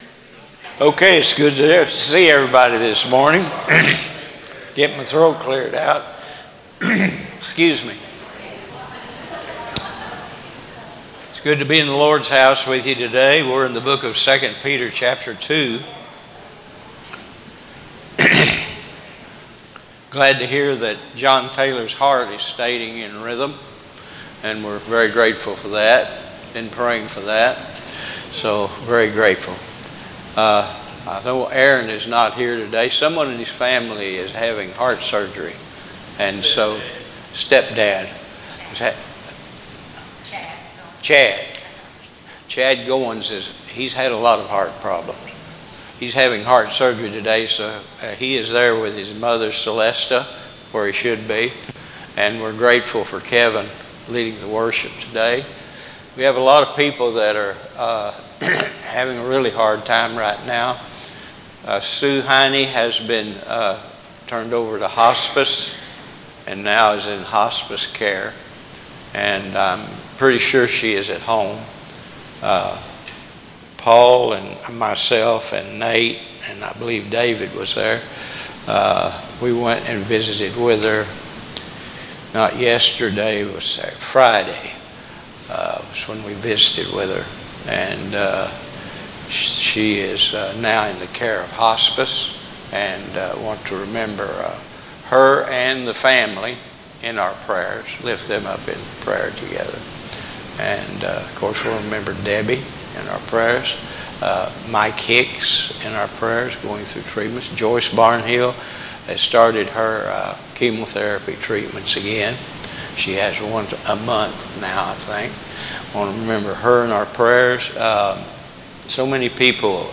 Sermons | Waldo Baptist Church